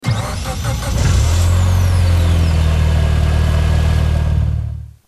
enginestart.mp3